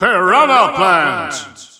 Announcer pronouncing Piranha Plant's name in Dutch.
Piranha_Plant_Dutch_Announcer_SSBU.wav